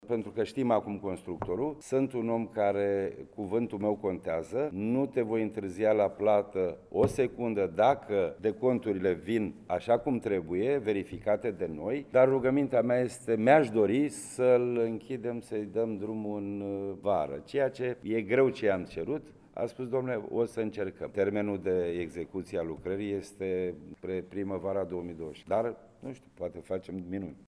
Președintele Consiliului Județean Constanța, Florin Mitroi: